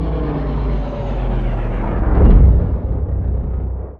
liftstop.wav